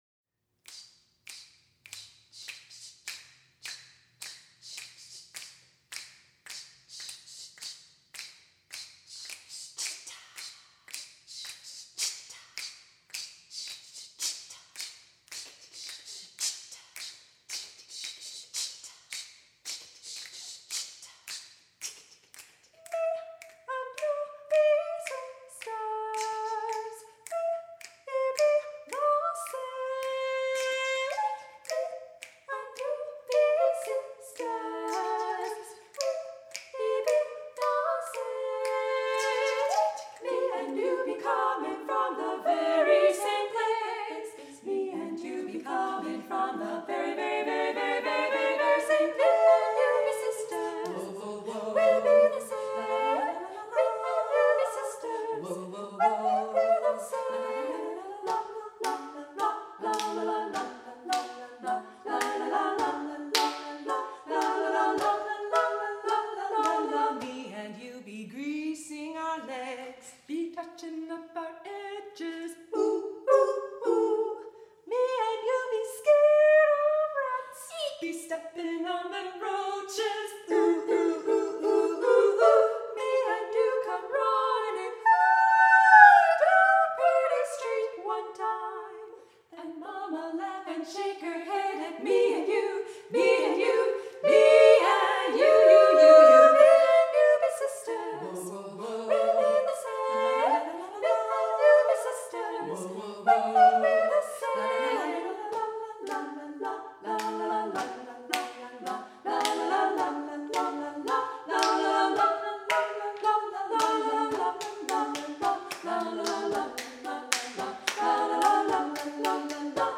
for SSA Chorus (1998)